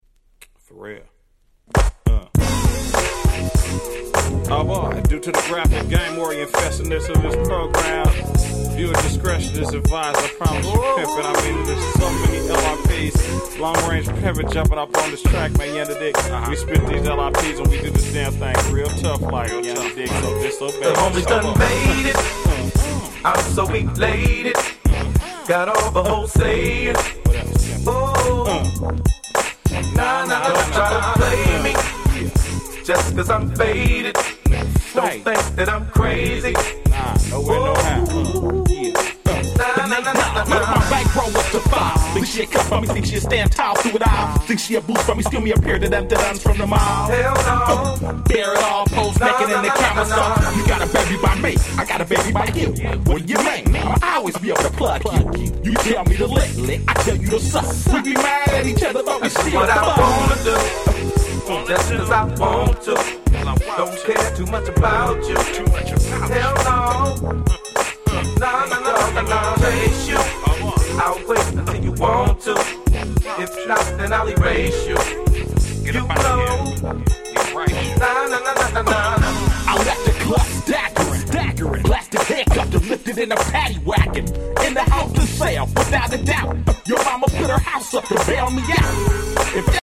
00' Nice West Coast Hip Hop !!